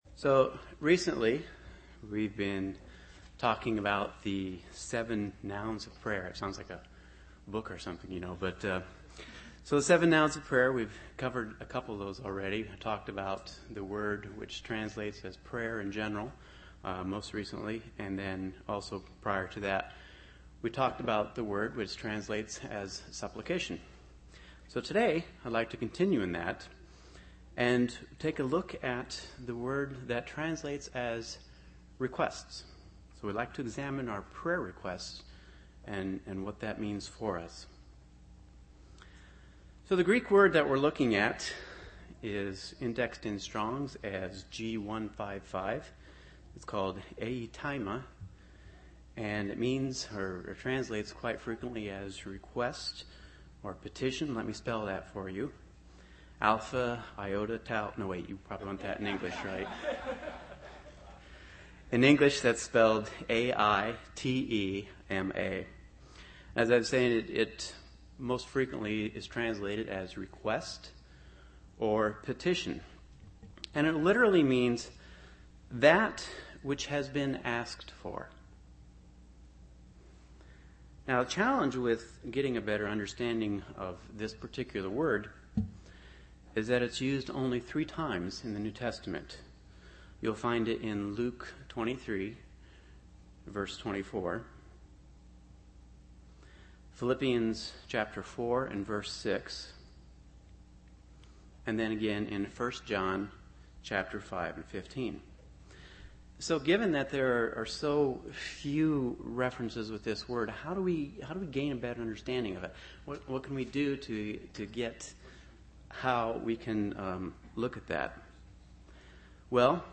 UCG Sermon Studying the bible?
Given in Chicago, IL